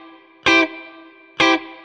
DD_StratChop_130-Cmaj.wav